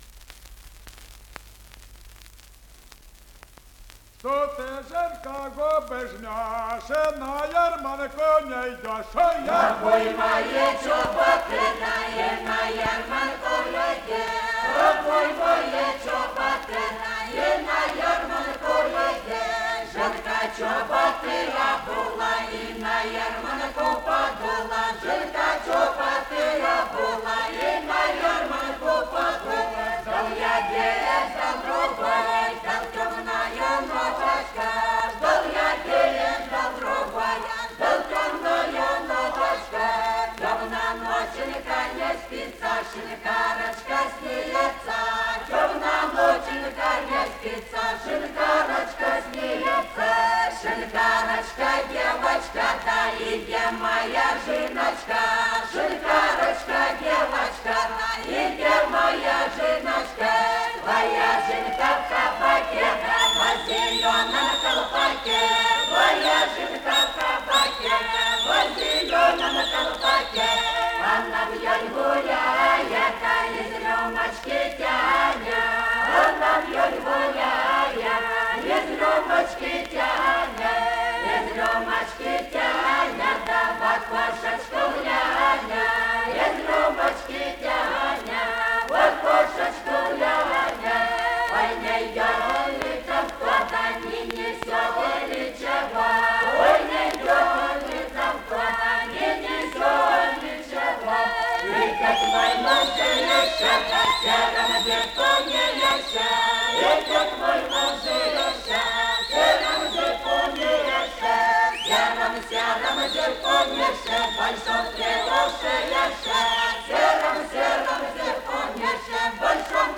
Плясовая песня
в исполнении ансамбля донских казаков хутора Мрыховского Ростовской области